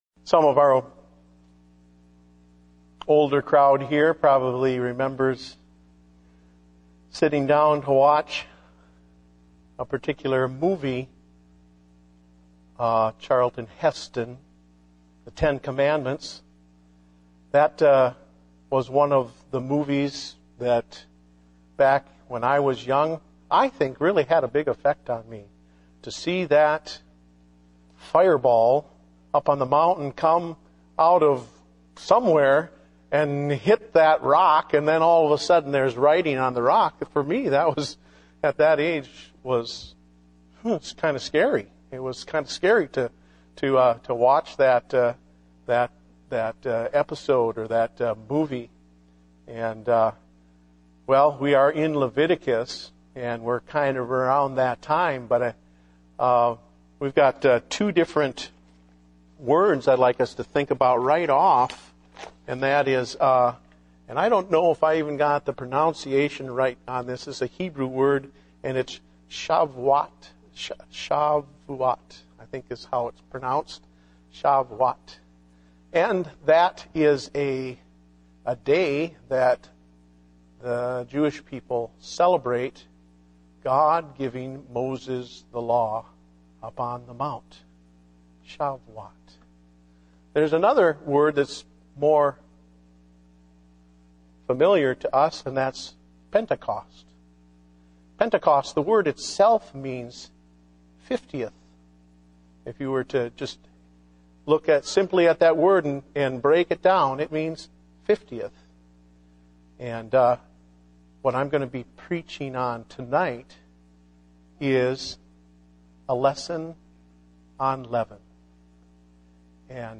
Date: February 21, 2010 (Evening Service)